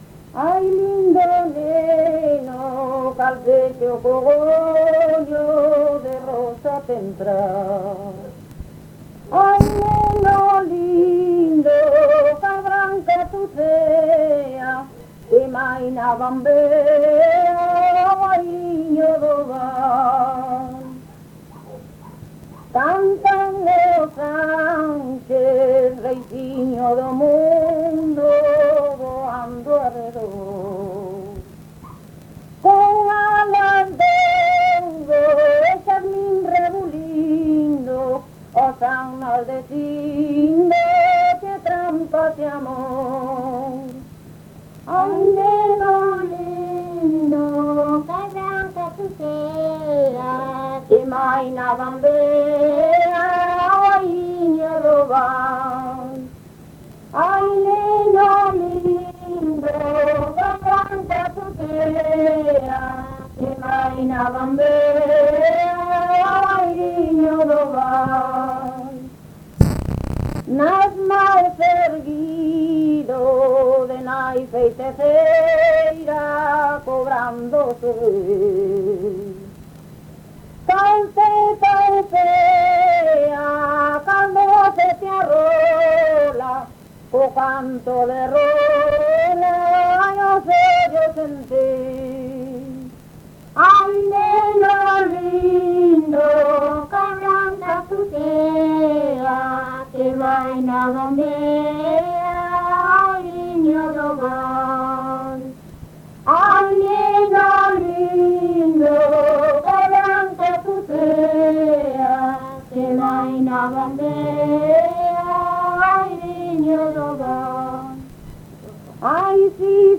Canto de Nadal: Ai Neno lindo
Concello: Chantada.
Áreas de coñecemento: LITERATURA E DITOS POPULARES > Cantos narrativos
Soporte orixinal: Casete
Datos musicais Refrán
Instrumentación: Voz
Instrumentos: Voz feminina